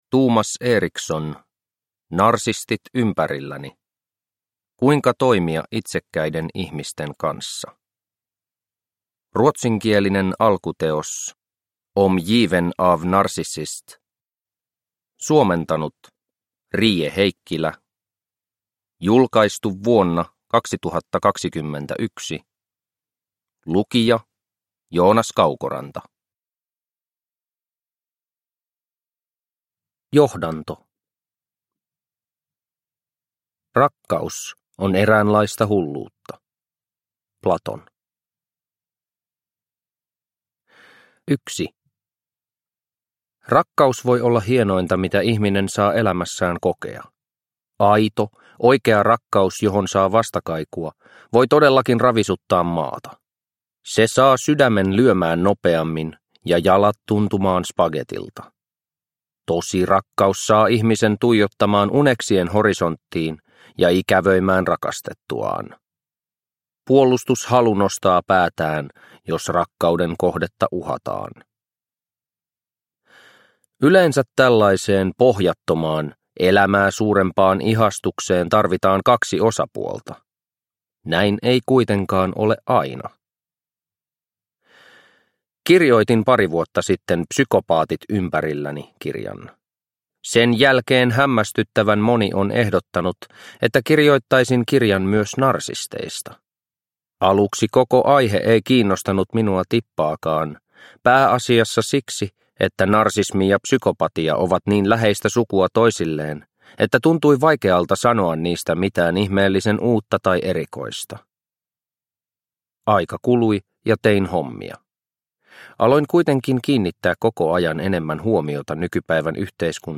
Narsistit ympärilläni – Ljudbok – Laddas ner